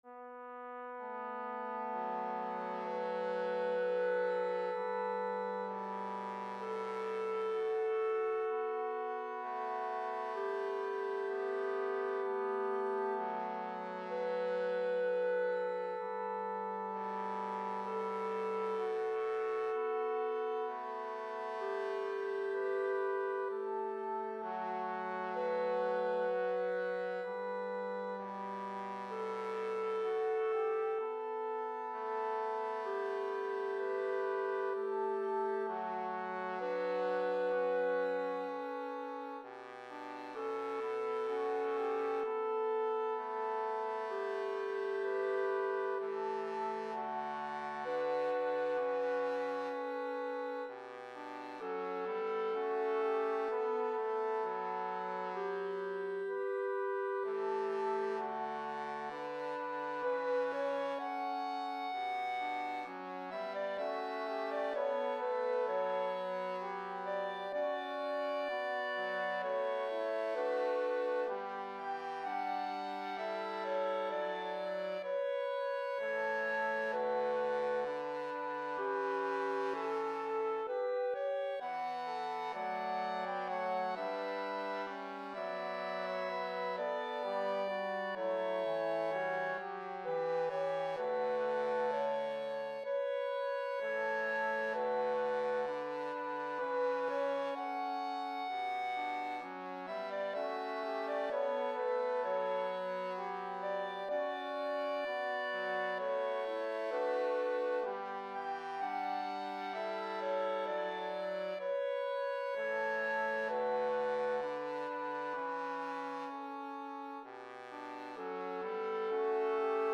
Voicing/Instrumentation: SATB
Related song categories are: Praise